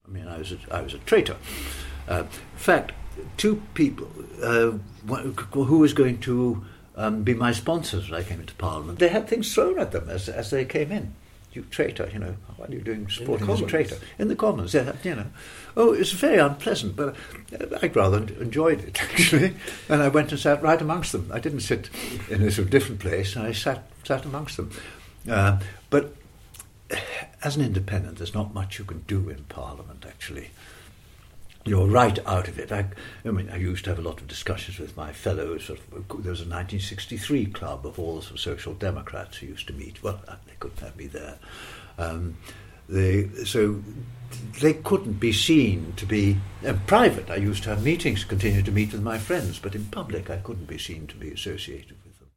As a committed pro-European he rebelled against the party leadership and voted for joining the EEC, and in doing so lost the support of his constituency party. In his recent interview for our oral history project, he discussed how he left the Labour party and forced a by-election, standing as an independent social democrat candidate: